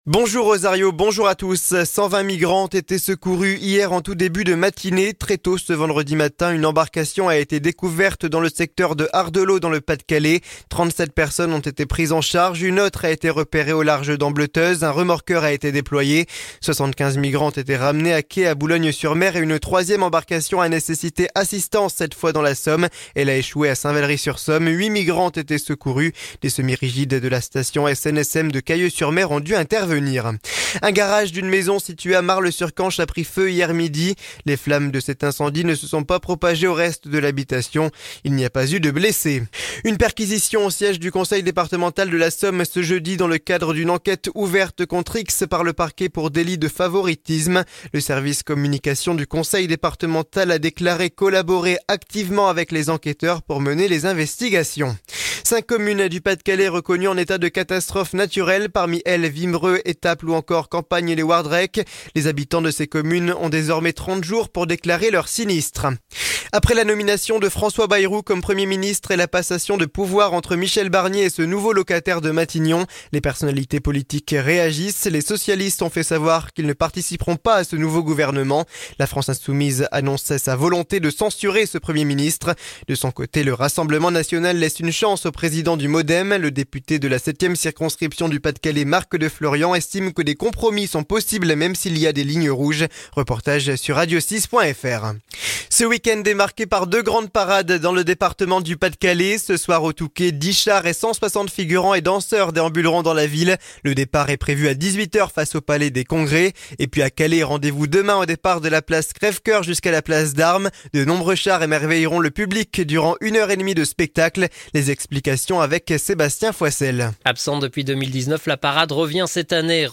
Le journal du samedi 14 décembre 2024